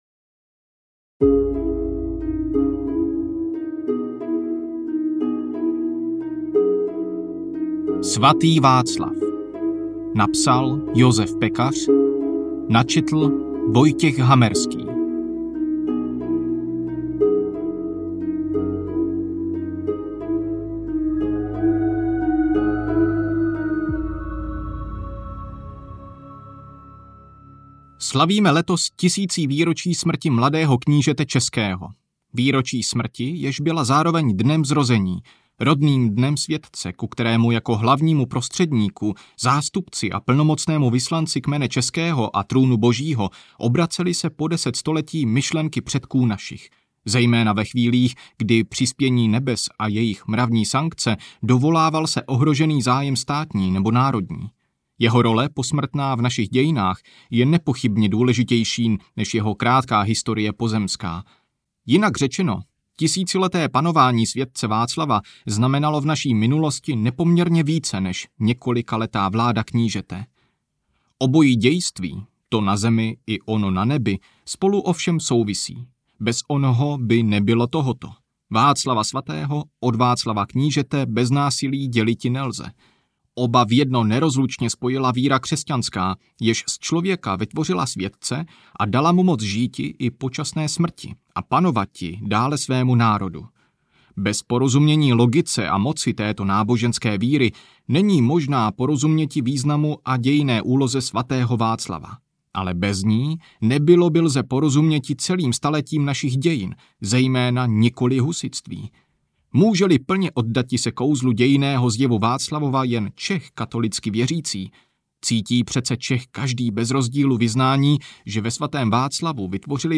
Svatý Václav audiokniha
Ukázka z knihy